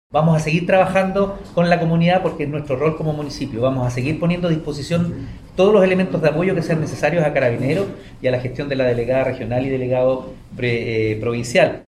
04-ALCALDE-OSCAR-CALDERON-Poner-a-disposicion.mp3